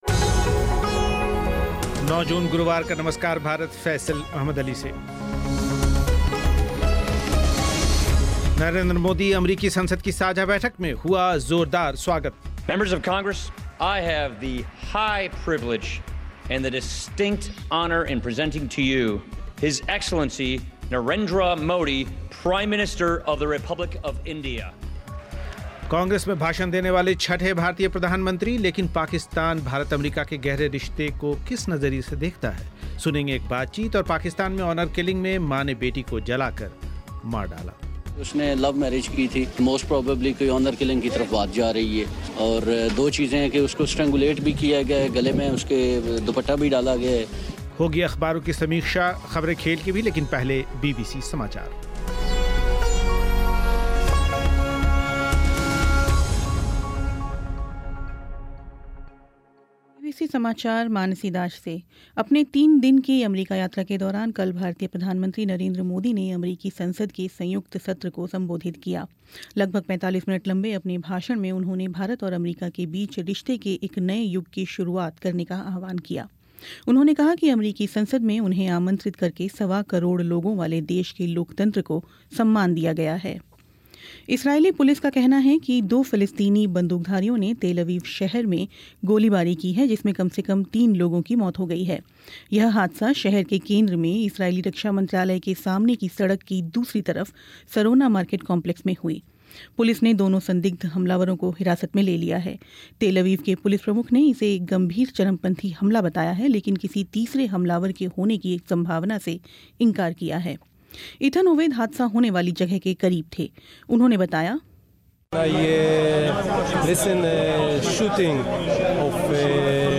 नरेंद्र मोदी अमरीकी संसद की साझा बैठक में, हुआ ज़ोरदार स्वागत, सुनें उनके भाषण के अंश